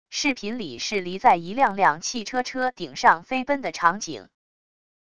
视频里是离在一辆辆汽车车顶上飞奔的场景wav音频